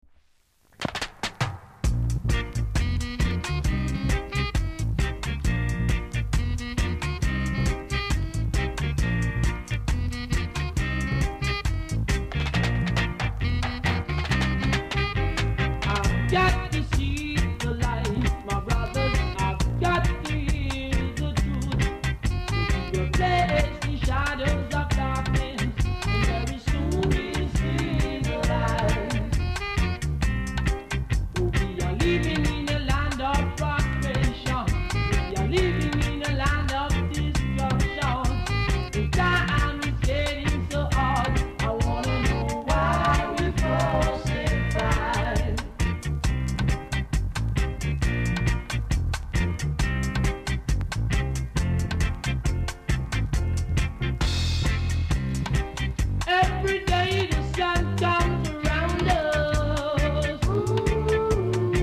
※小さなチリノイズが少しあります。
コメント NICE ROOTS!!